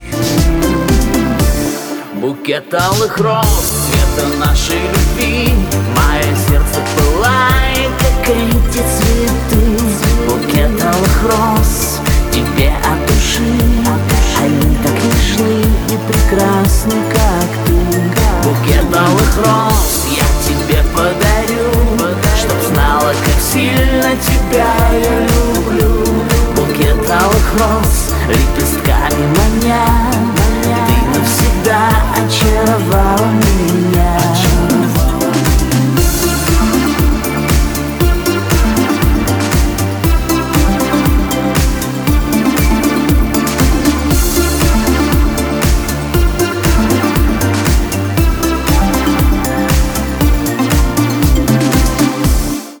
поп
диско
русский шансон